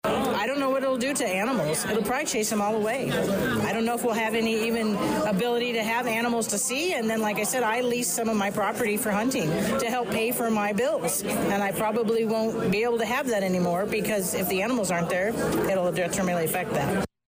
A capacity crowd of about 50 filled the City Hall Chambers; with 20 residents, some of them speaking more than once, all speaking against the Summit Ridge Energy proposal, which would be on land owned by Martin Farms out of Indiana.